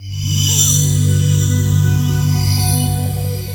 CHORD48 01-R.wav